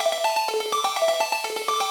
SaS_Arp04_125-A.wav